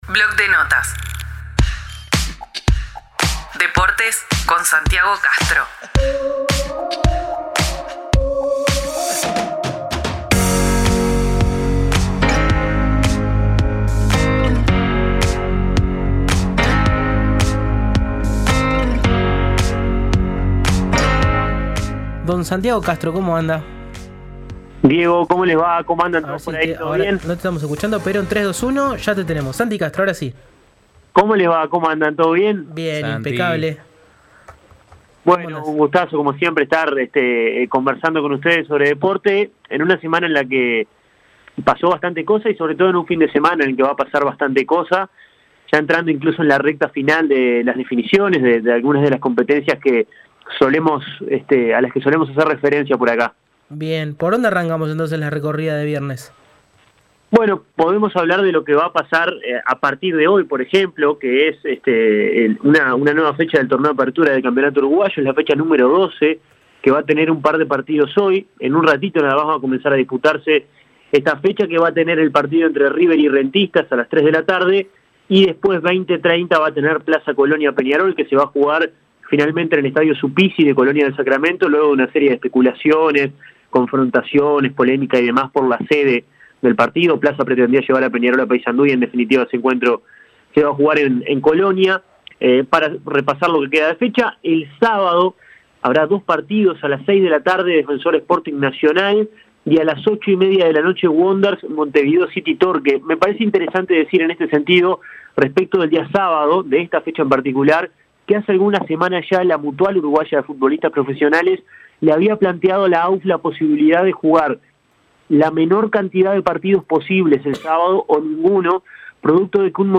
Comunidad Udelar, el periodístico de UNI Radio.